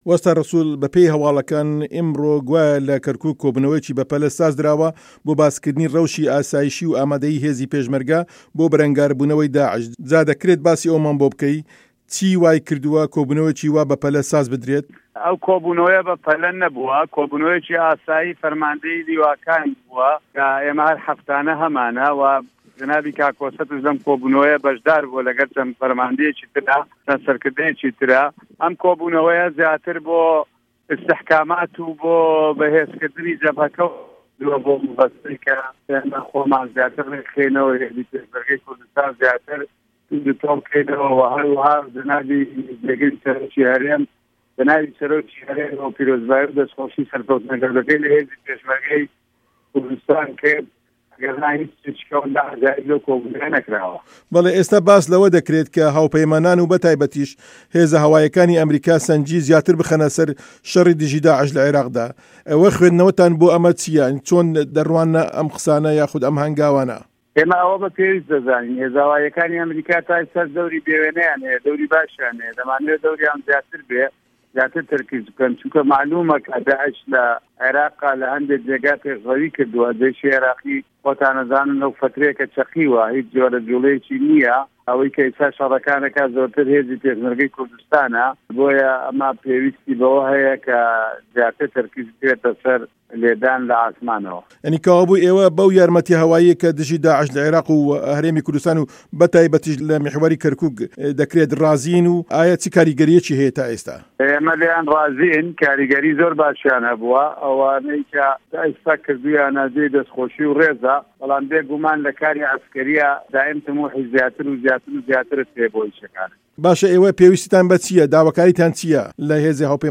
وتووێژی وه‌ستا ڕه‌سوڵ